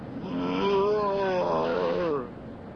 mgroan5.ogg